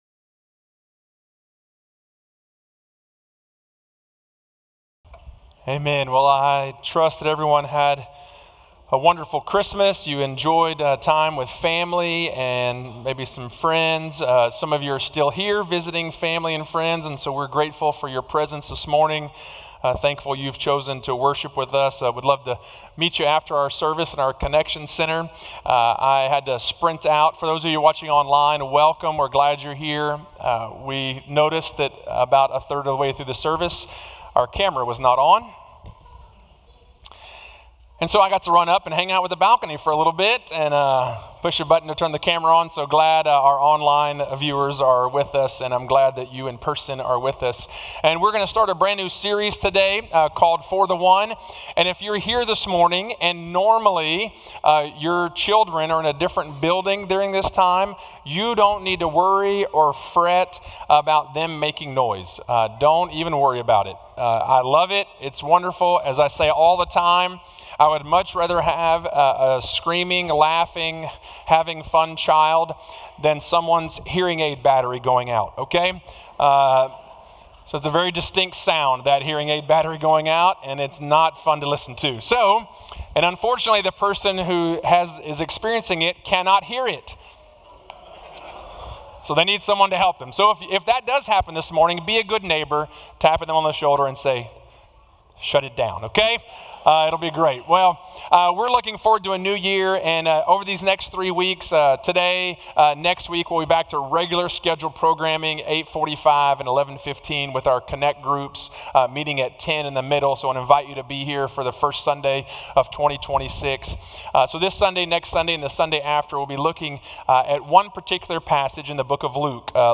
Sermons
Sermons from First Baptist Friendswood: Friendswood, TX